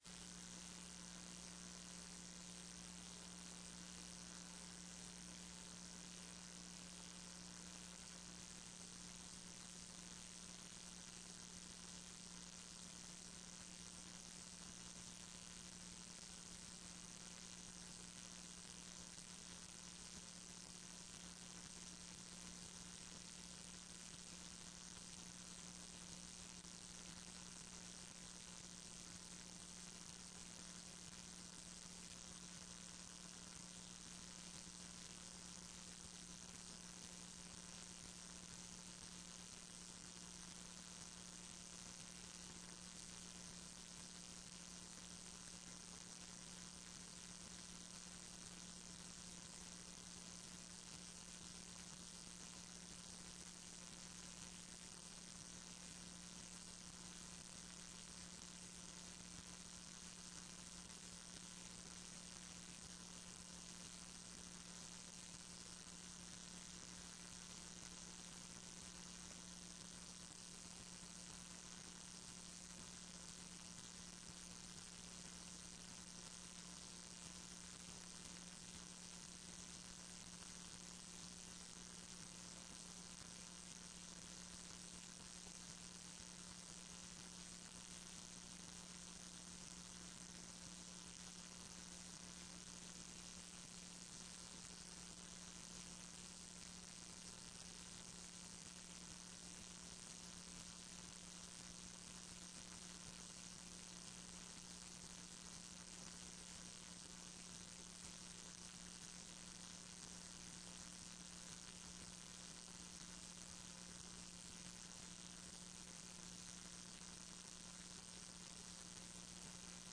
TRE-ES - Áudio da sessão 20.10.14